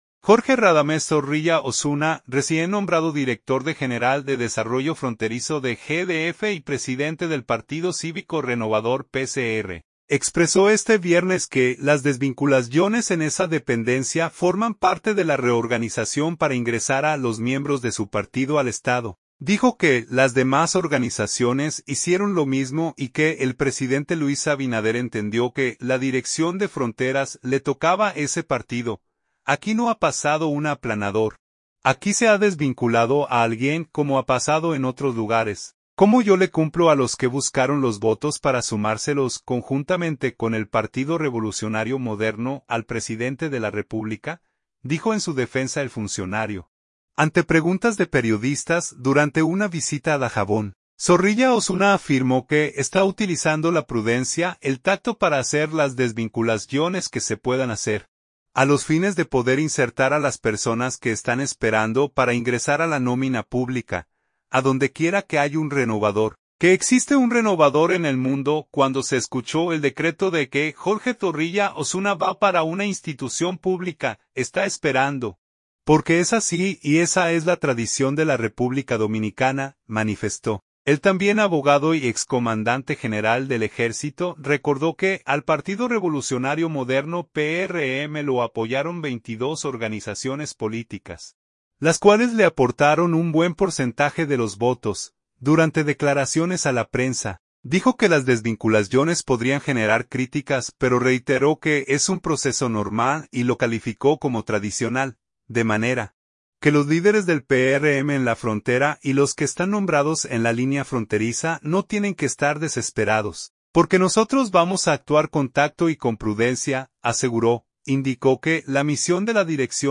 Durante declaraciones a la prensa, dijo que las desvinculaciones podrían generar críticas, pero reiteró que es un proceso "normal" y lo calificó como tradicional.